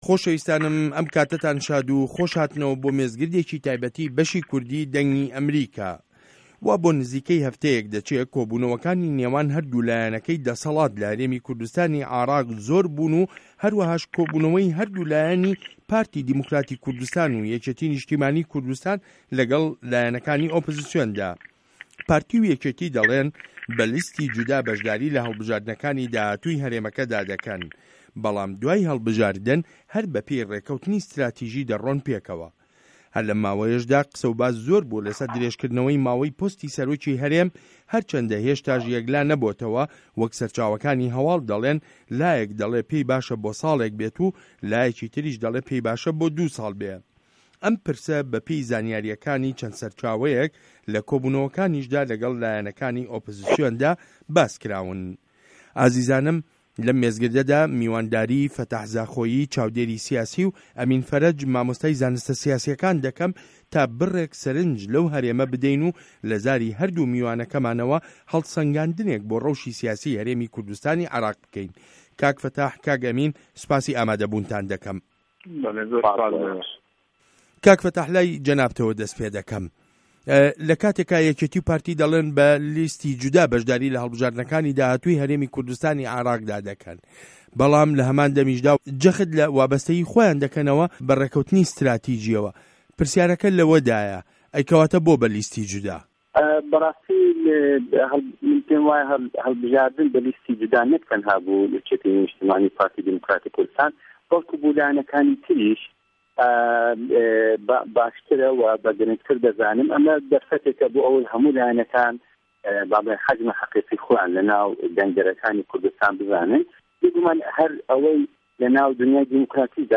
مێزگرد : هه‌ڵبژاردن و دیموکراسی له‌ هه‌رێمی کوردستانی عێراق